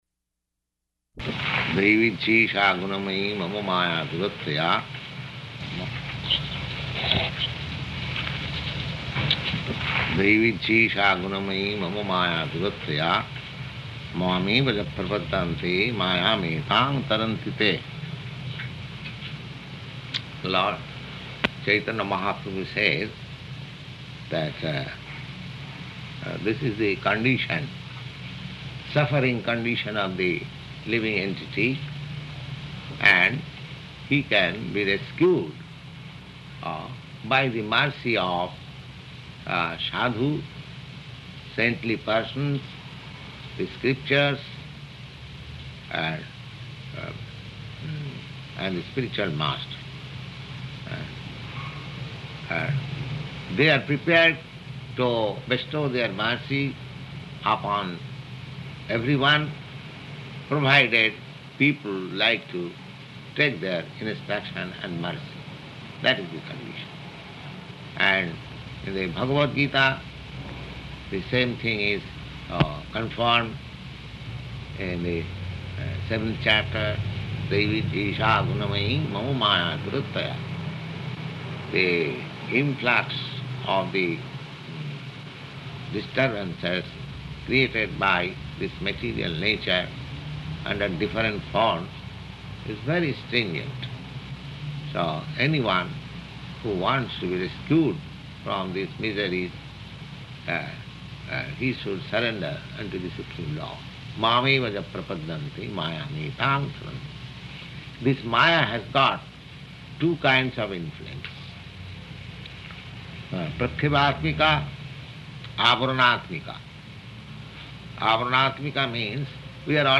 Type: Caitanya-caritamrta
Location: New York